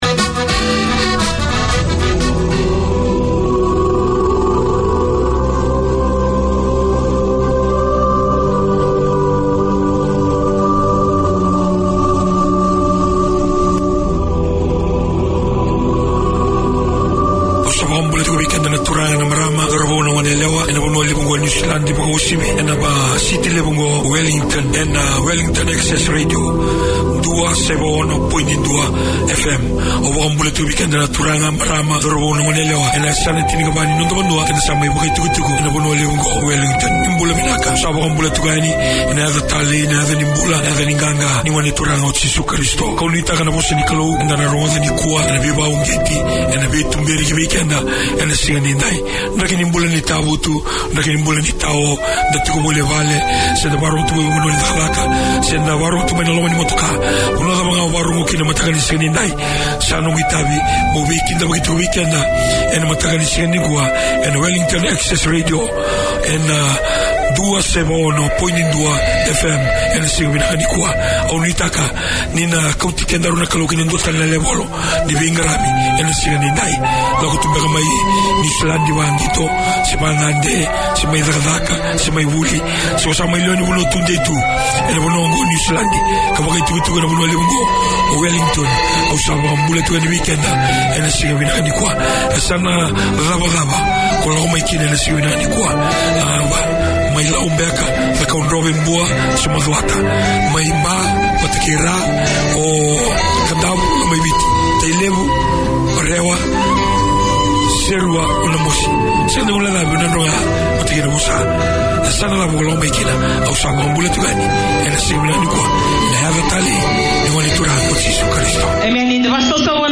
This is co-operative airtime shared between three Niuean Christian churches from around the Auckland region. The churches come to your place with a weekly rotation of services including preaching, singing, playing and praying. There are gospel songs and gospel lessons, praise and testifying.